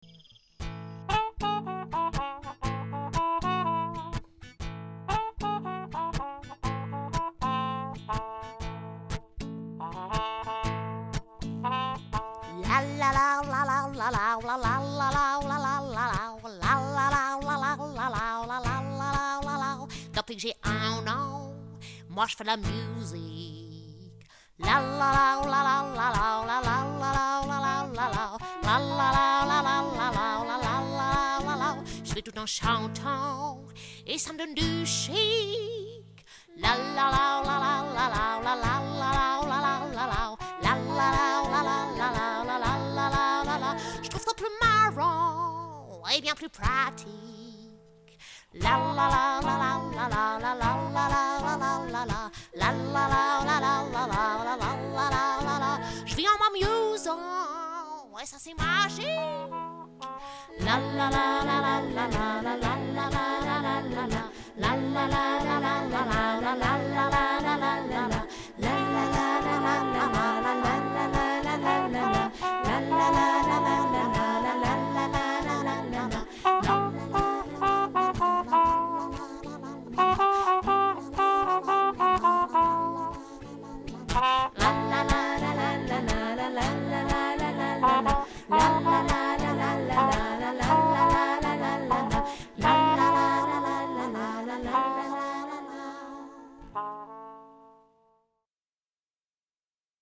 Gaieté et poésie sont au programme de ce spectacle musical pour les tout-petits!
08-le-swing-de-dame-souris.mp3